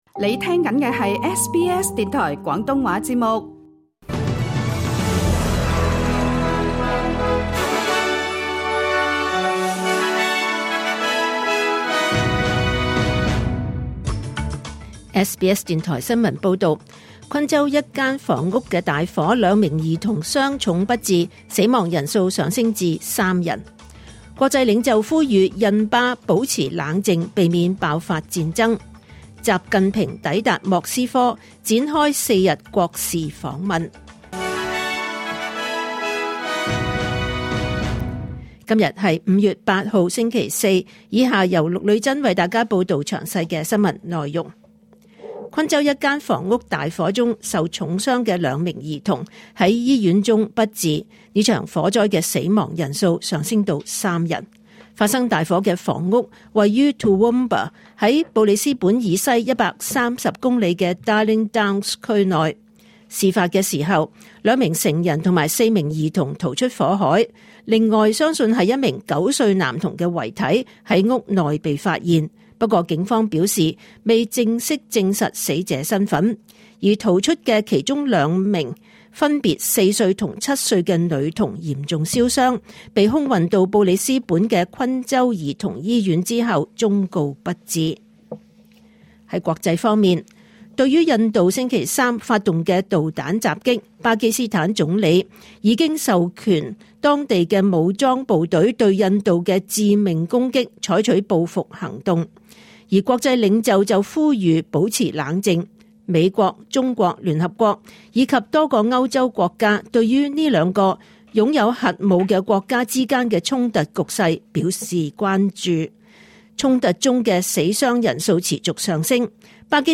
2025年5月8日SBS 廣東話節目九點半新聞報道。